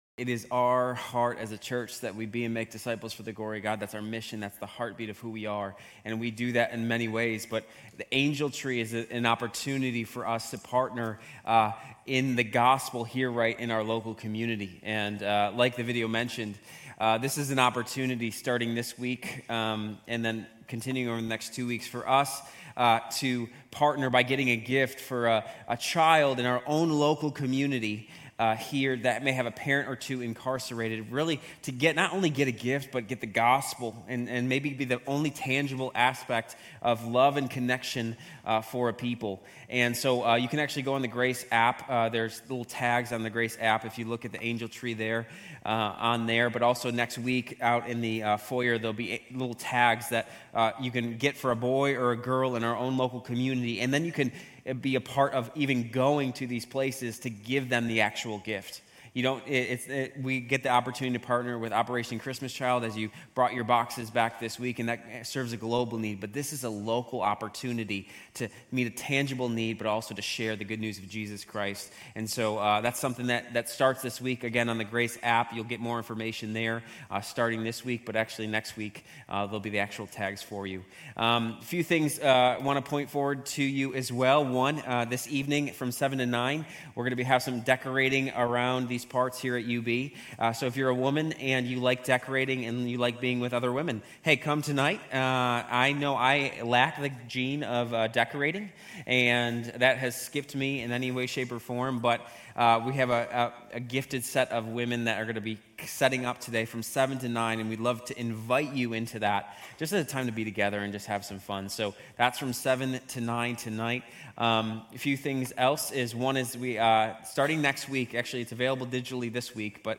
Grace Community Church University Blvd Campus Sermons Gen 37-50 - Joseph Nov 24 2024 | 00:32:42 1x 00:00 / 00:32:42 Subscribe Share RSS Feed Share Link Embed